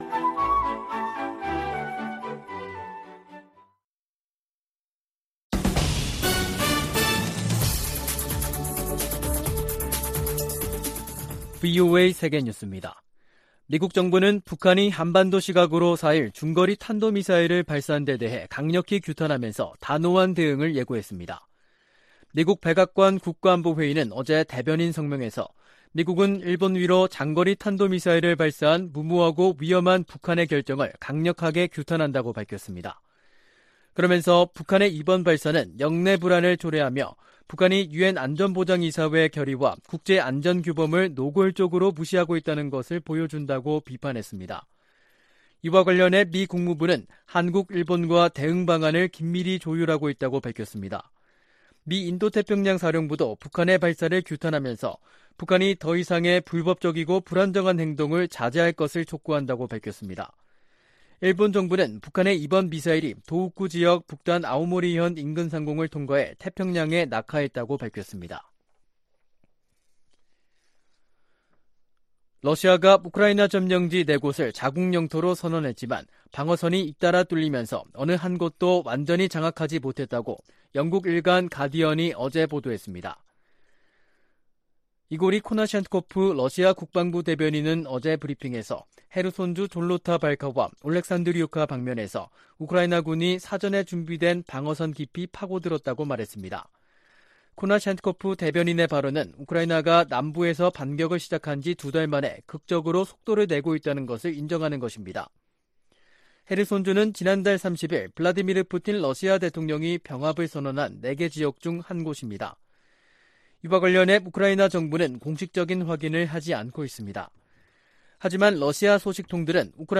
VOA 한국어 간판 뉴스 프로그램 '뉴스 투데이', 2022년 10월 4일 2부 방송입니다. 북한이 4일 일본열도를 넘어가는 중거리 탄도미사일(IRBM)을 발사했습니다.